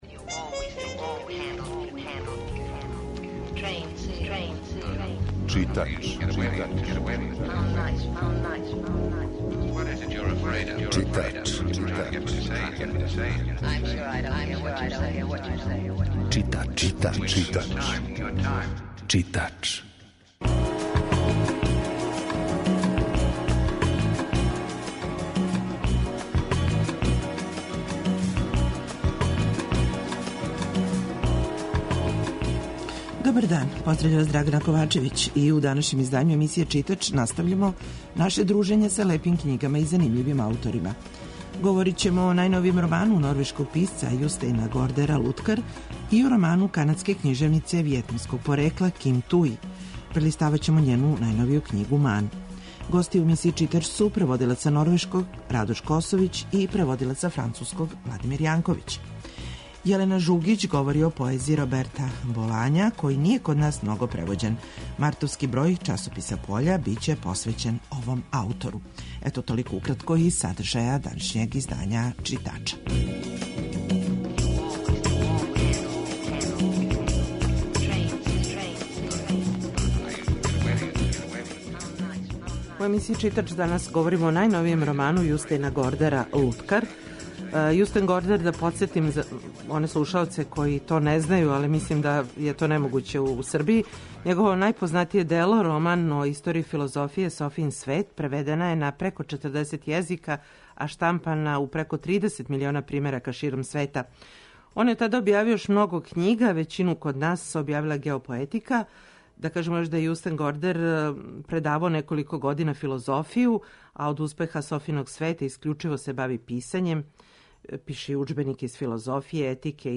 Гост у студију Радио Београда 2